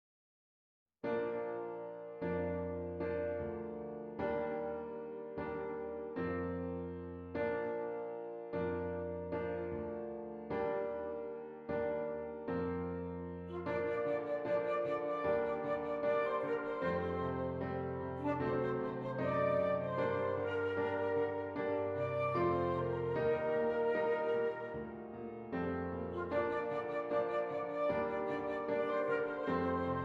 Flute Solo with Piano Accompaniment
B Flat Major
Moderately slow